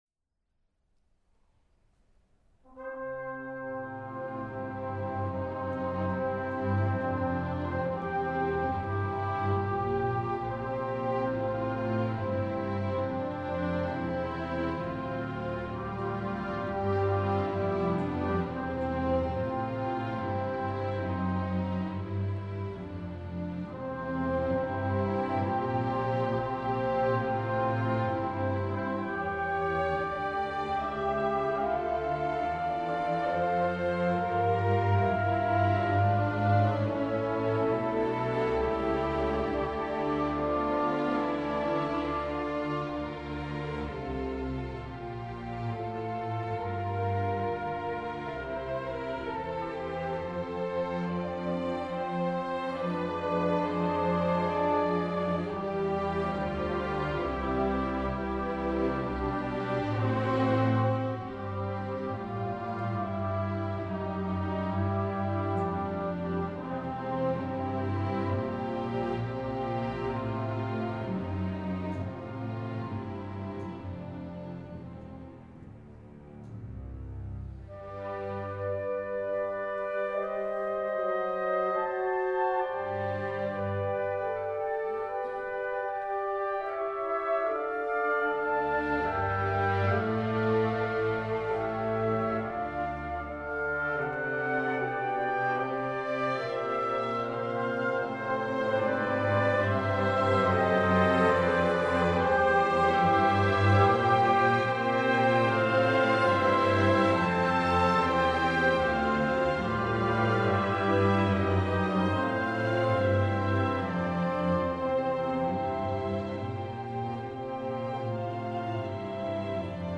Robert Schumann: Sinfonía nº 2 en do mayor, Op. 61 | Euskadiko Orkestra - Basque National Orchestra
Robert Schumann: Sinfonía nº 2 en do mayor, Op. 61